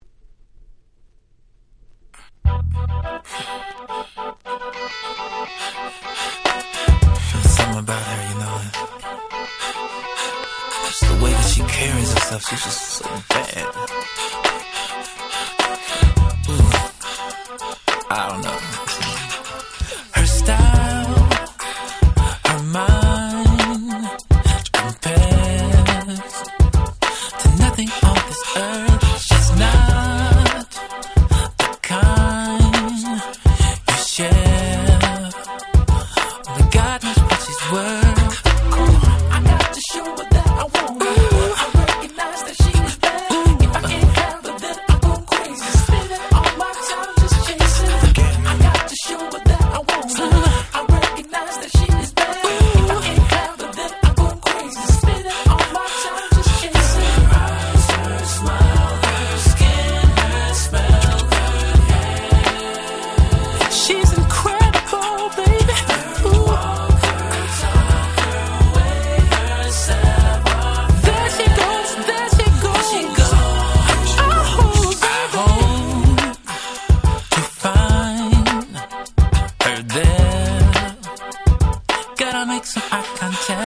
01' Smash Hit R&B !!